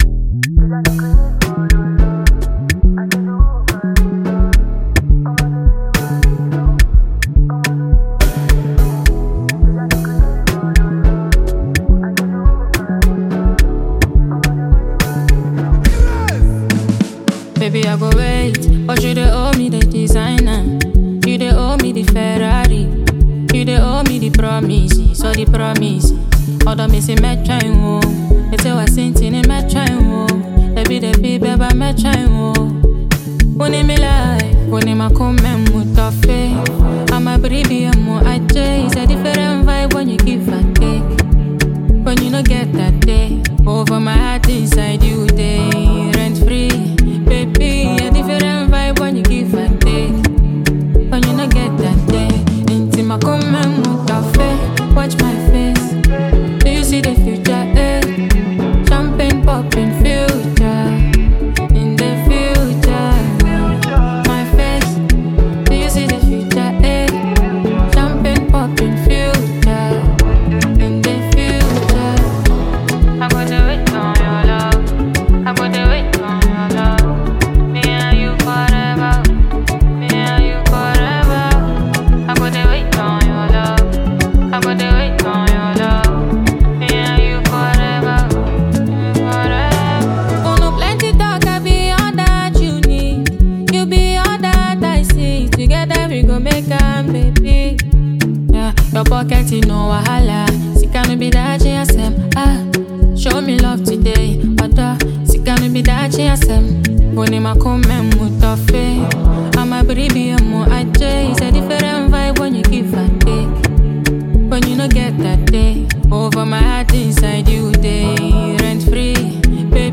soulful tune
Ghanaian female artiste